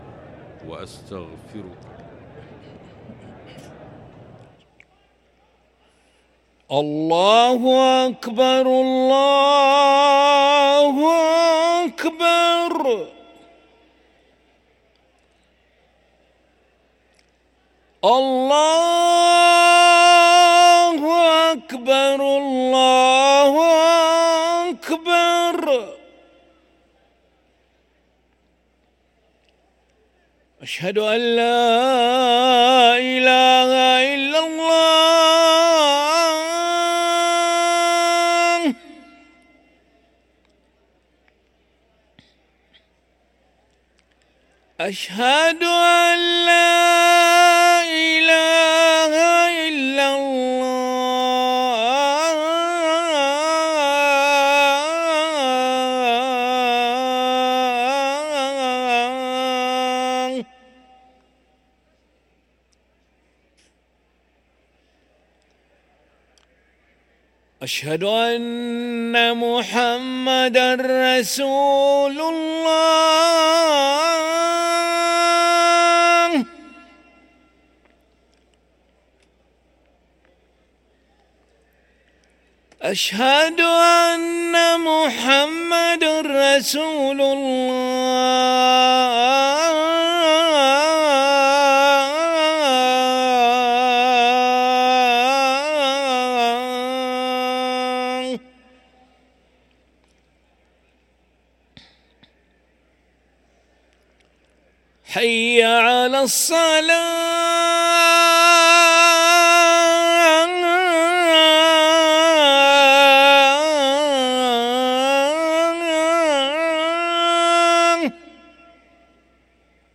أذان العشاء للمؤذن علي ملا الأحد 25 صفر 1445هـ > ١٤٤٥ 🕋 > ركن الأذان 🕋 > المزيد - تلاوات الحرمين